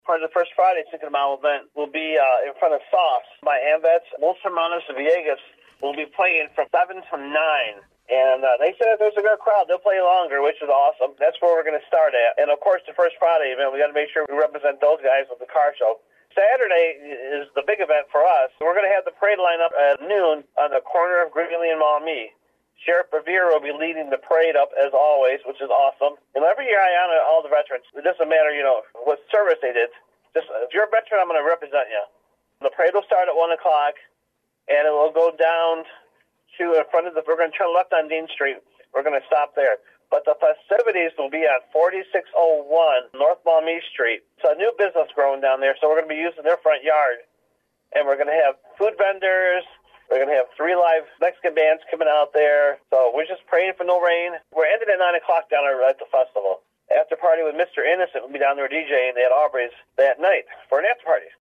is assisting the City of Adrian with the planning and preparation for the events…he spoke with WLEN News about the schedule…